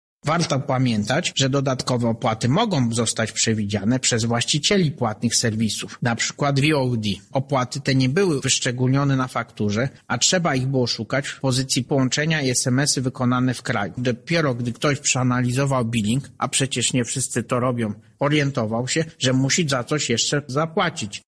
-mówi prezes Urzędu Ochrony Konkurencji i Konsumentów Marek Niechciał.